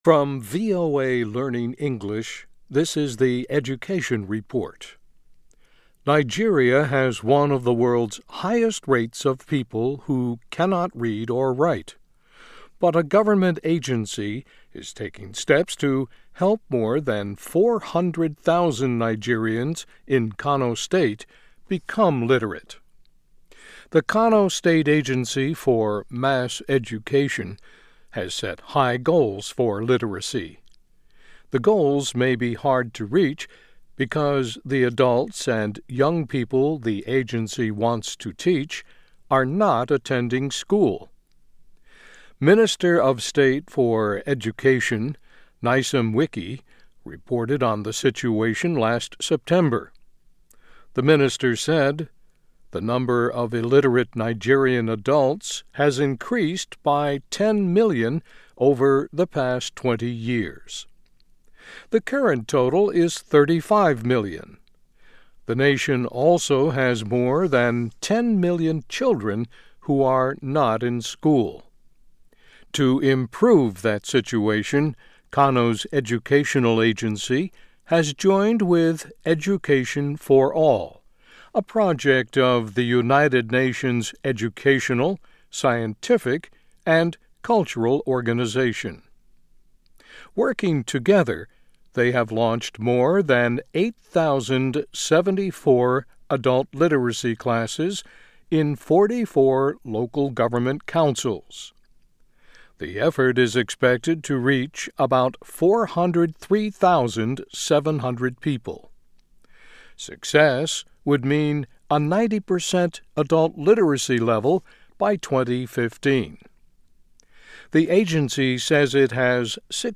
VOA Special English, Education Report, Northern Nigeria Launches Massive Literacy Campaign